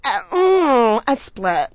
oh_a_split_3.wav